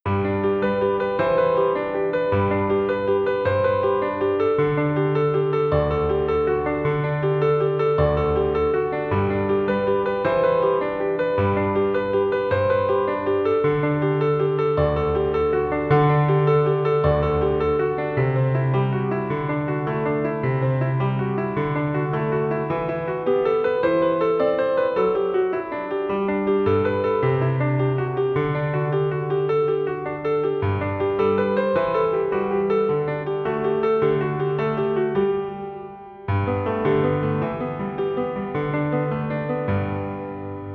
Piano solo (bucle)
piano
melodía
repetitivo